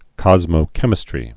(kŏzmō-kĕmĭ-strē)